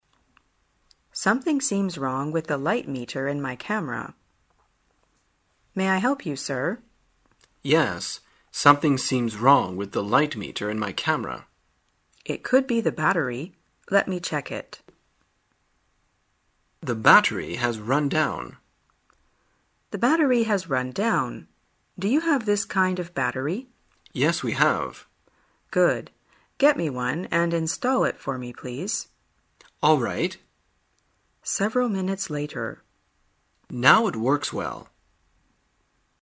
旅游口语情景对话 第291天:如何说明相机出问题